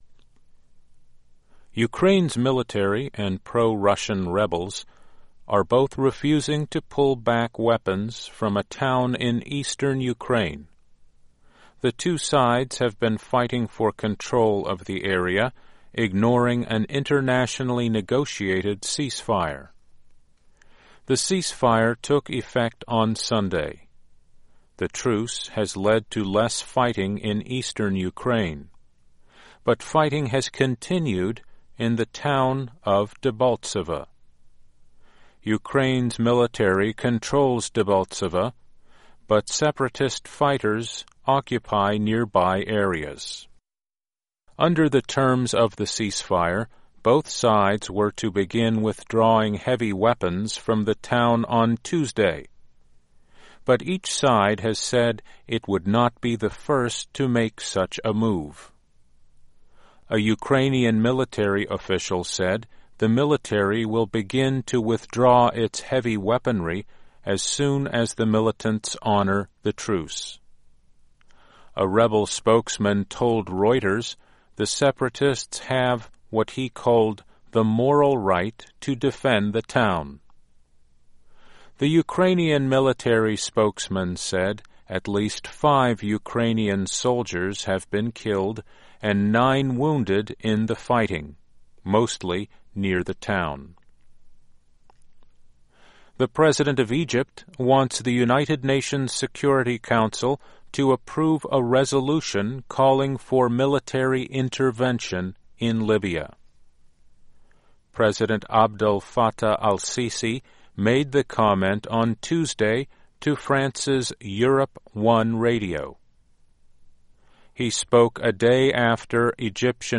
This newscast was based on stories from VOA’s News Division.